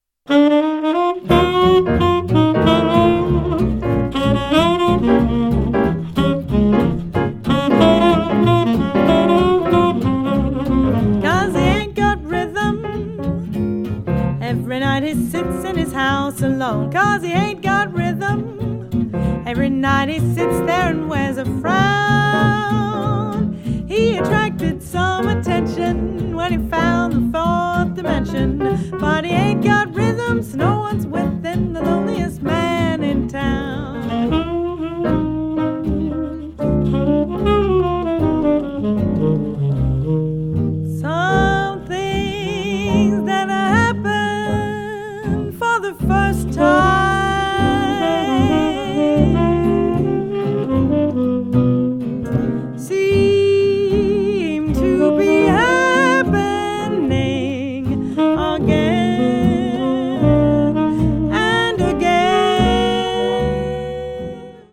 La trompette délicate et inspirée
chant, violon
trompette
saxophone ténor
chant, guitare
piano
contrebasse